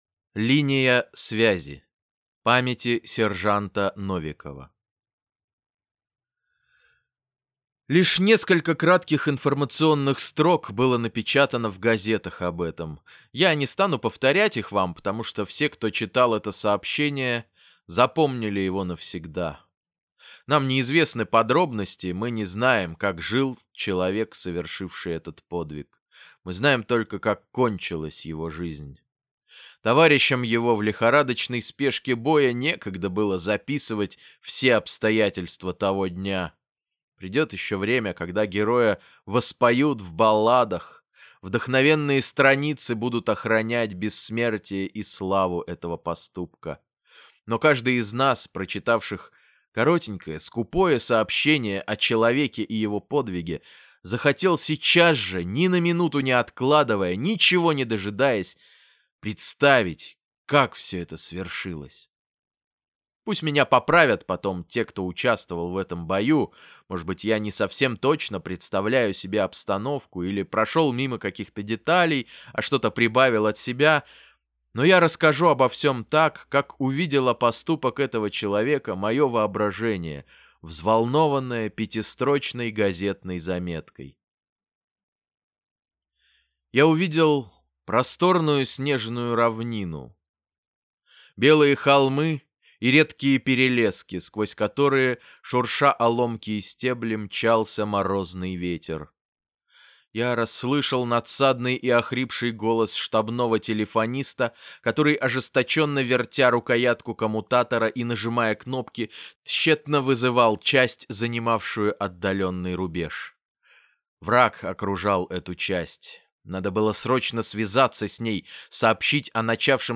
Линия связи - аудио рассказ Льва Кассиля - слушать онлайн